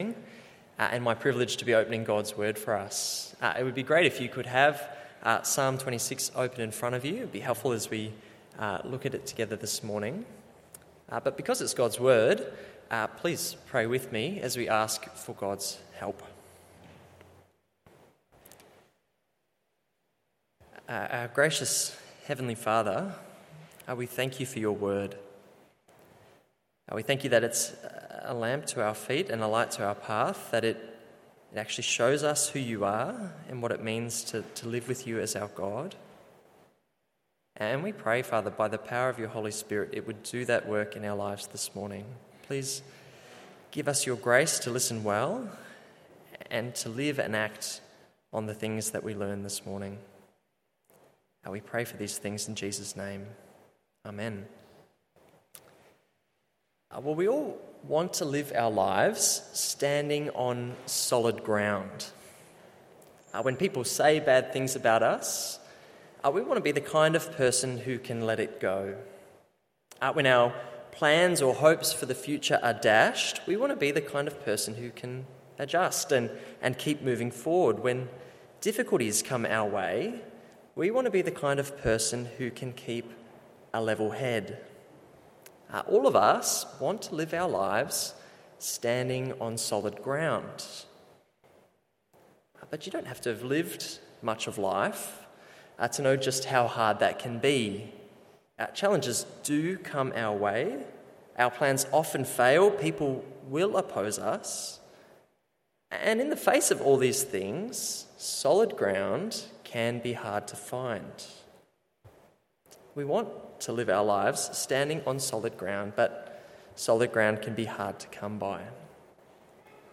Morning Service Psalm 26…